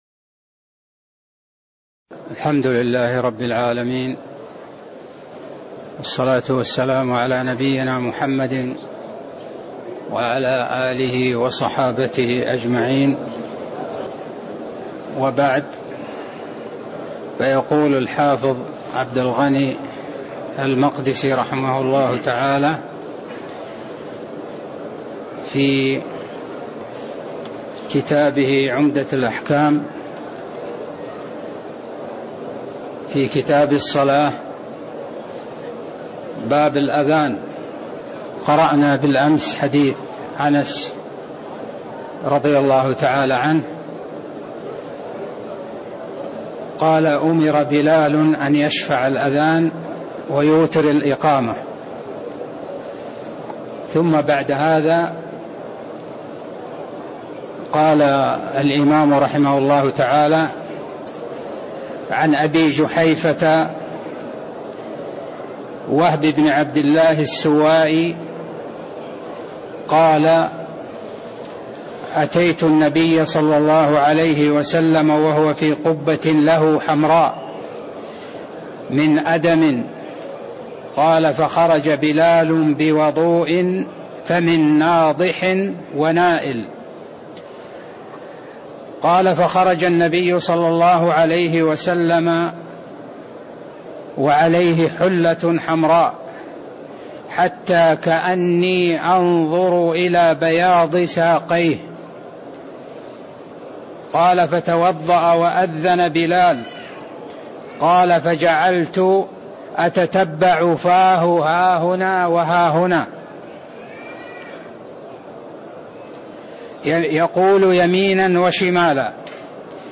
تاريخ النشر ٢٠ شعبان ١٤٣٥ هـ المكان: المسجد النبوي الشيخ